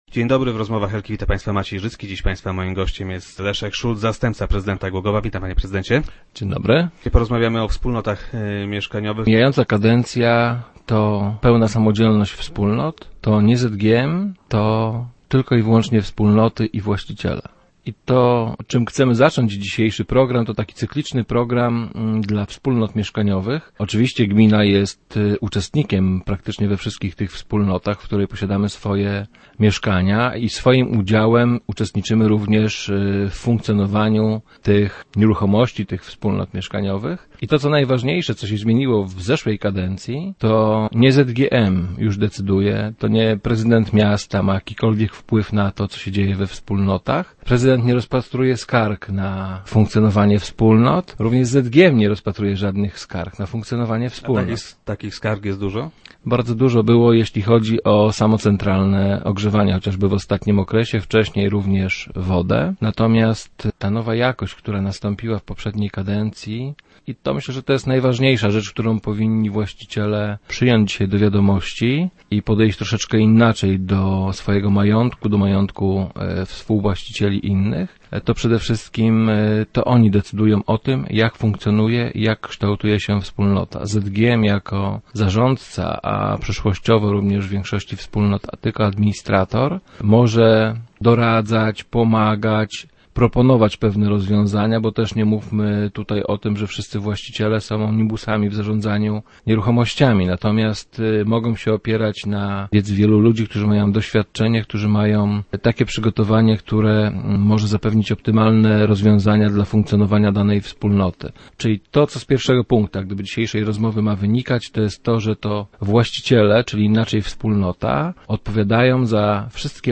- Gmina jest uczestnikiem niemal we wszystkich tych wspólnotach, bowiem ciągle ma nich swoje mieszkania. To co się zmieniło w minionej kadencji, to fakt, że to już nie Zakład Gospodarki Mieszkaniowej czy też prezydent miasta, mają wpływ na to, co dzieje się we wspólnotach - powiedział wiceprezydent Szulc w dzisiejszych Rozmowach Elki.